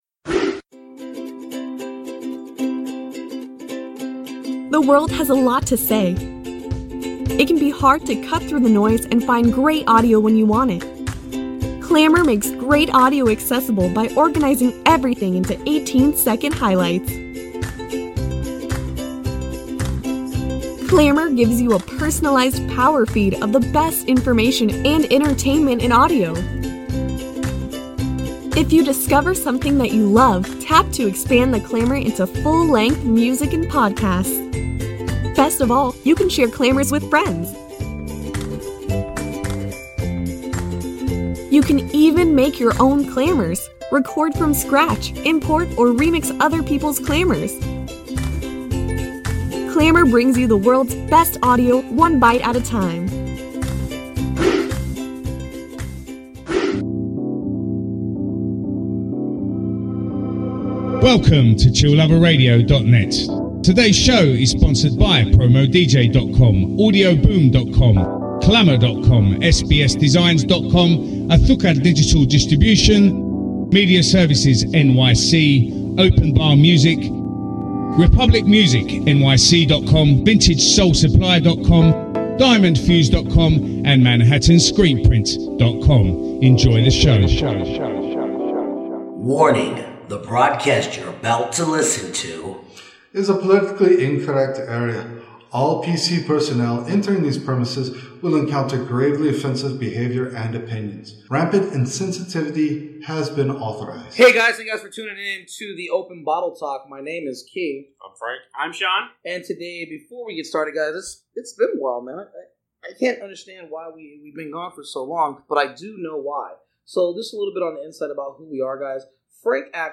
Styles: Beer Talk, Beer News, Beer, Craft Beers, Talk Show